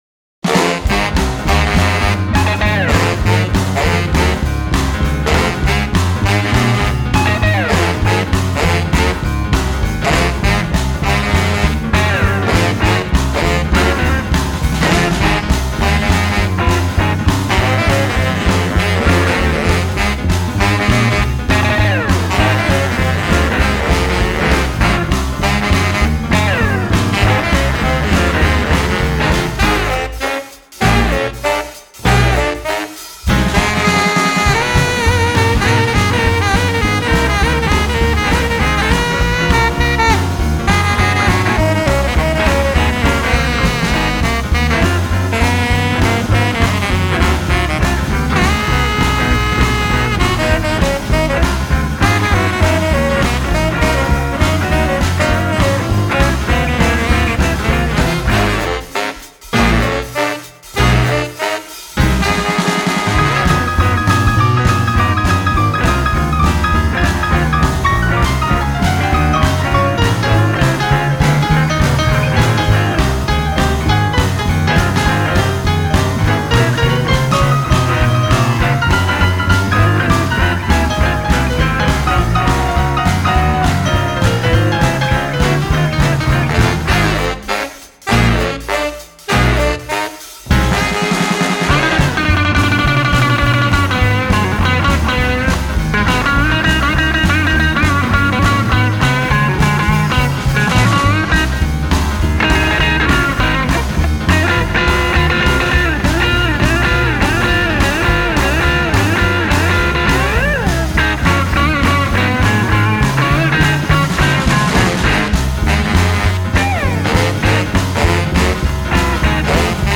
Gibson Lucille
• D'Addario standaard 0.10 op mijn Strat 0.11
Van lekker jazzy tot een fijne lichte scheur.